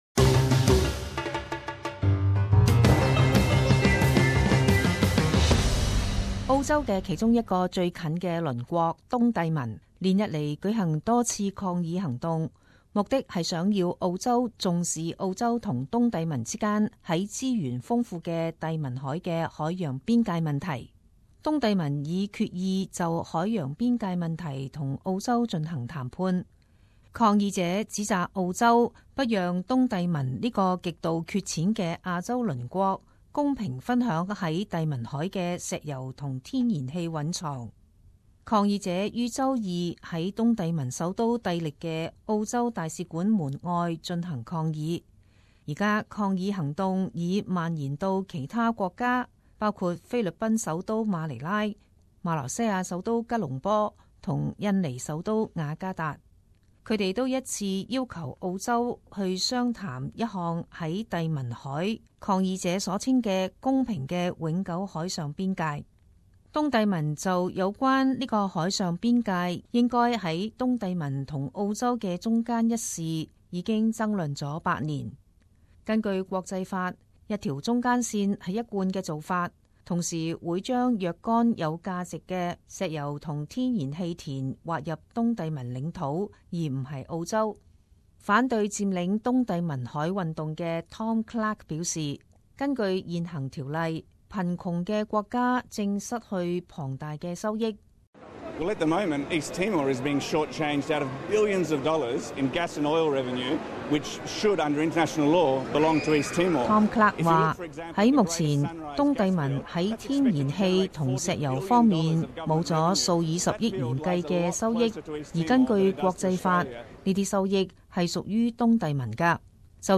时事报导 - 东帝汶与澳洲海洋边界争论